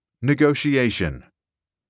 Negotiation [ni-goh-shee-ey-shuh n]